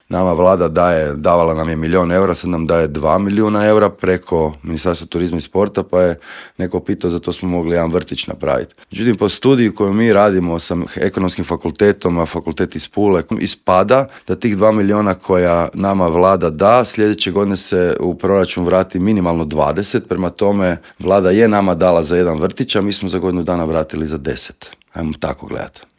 Osvrnuo se u intervjuu Media servisa na značaj utrke, utjecaj na gospodarstvo, stazu i na sve popratne događaje.